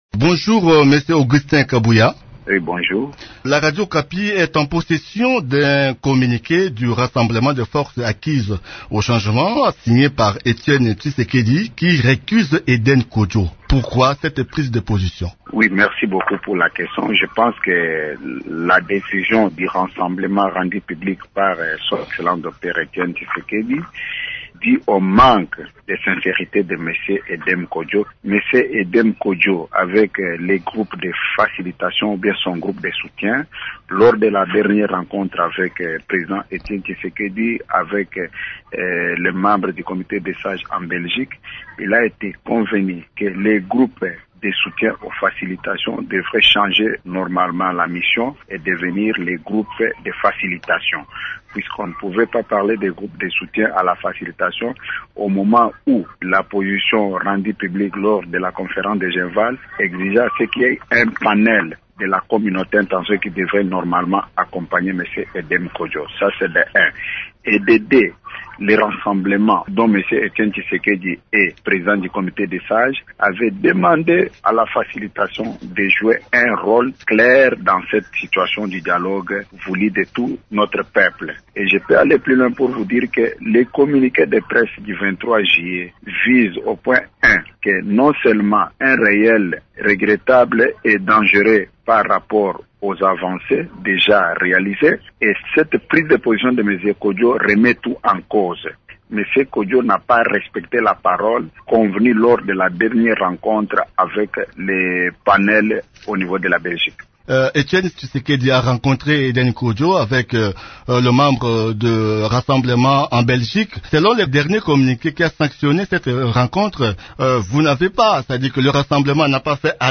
s’entretient avec